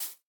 Minecraft Version Minecraft Version snapshot Latest Release | Latest Snapshot snapshot / assets / minecraft / sounds / block / bamboo / sapling_hit1.ogg Compare With Compare With Latest Release | Latest Snapshot
sapling_hit1.ogg